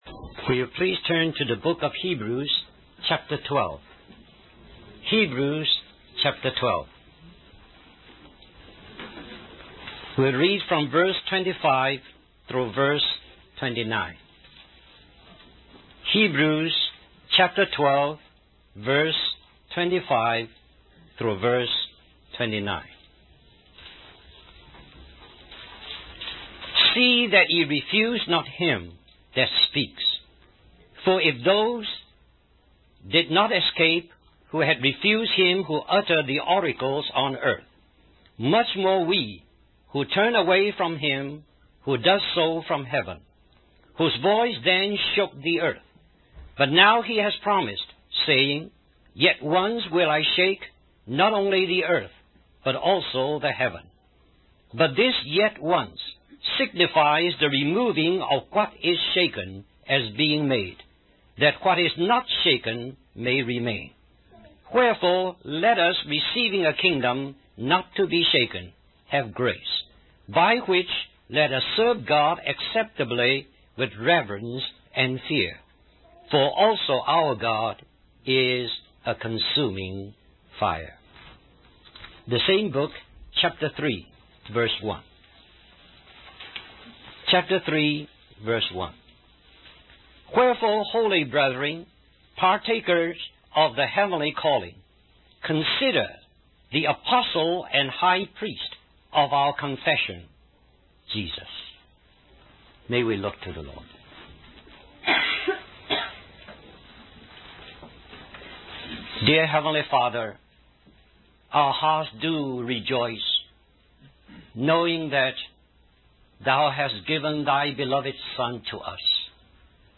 This sermon focuses on the humility and obedience of Jesus Christ as described in Philippians 2:5-11, highlighting how Jesus, being in the form of God, emptied Himself and took on the likeness of man, obedient even unto death on the cross. The sermon emphasizes the importance of considering Jesus' sacrificial love and obedience, urging believers to live in readiness for His imminent return and to worship Him as Lord.